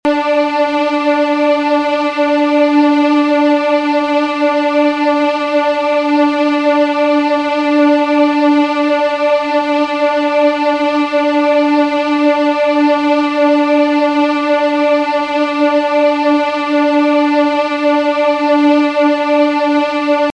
Referenzbeispiele zum Stimmen der E-, A-, D-, G- Geigensaite
Wenn Sie auf die folgenden Links klicken, hören Sie, wie die Saiten klingen und können ihre Geige danach stimmen:
D-Saite (mp3):
geige-stimmen-d-saite.mp3